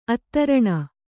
♪ attaraṇa